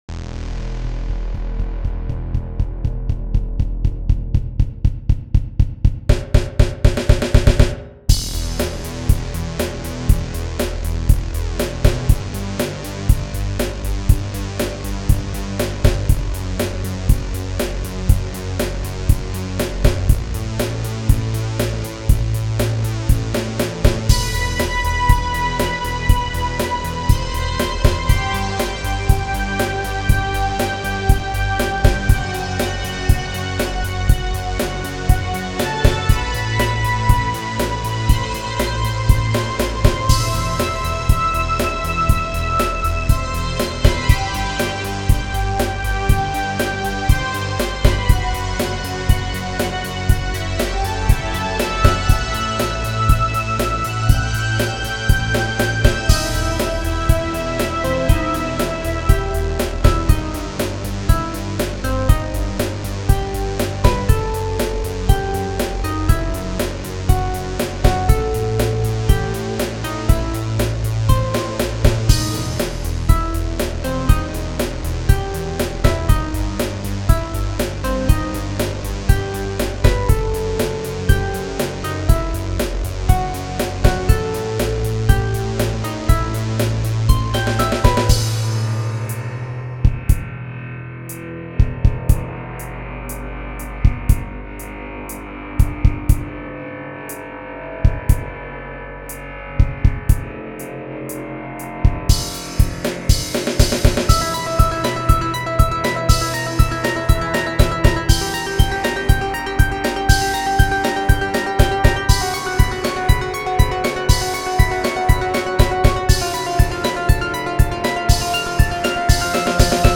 Alsa Modular Synth, ZynAddSubSubFX,
All the drums are performed by Hydrogen.